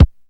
BattleCatKick.wav